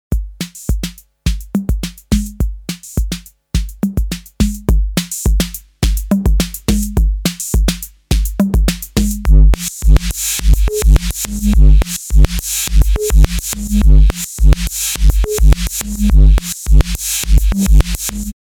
BOUM is a full analog stereo warming unit, combining an easy-to-use compressor, a versatile distortion generator and a smooth low-pass filter.
BOUM_DEMO_CompLight_Reverse_808_n_mp3.mp3